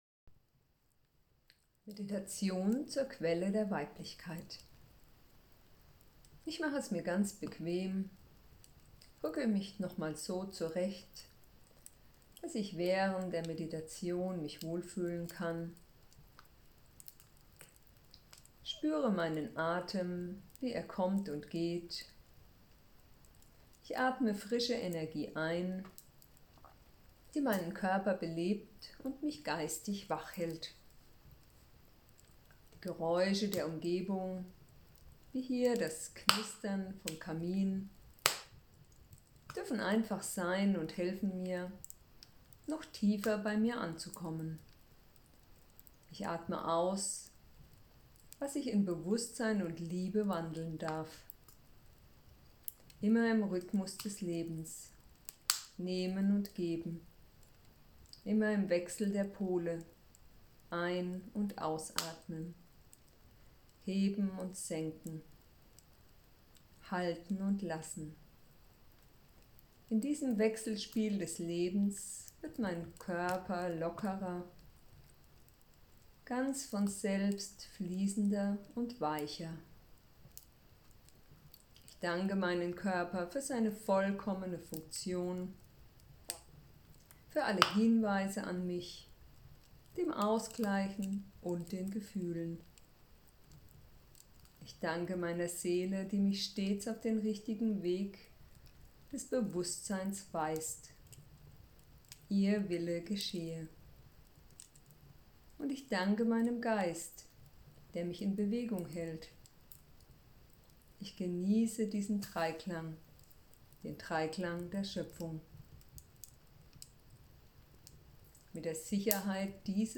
Meditation Heil-Traumreise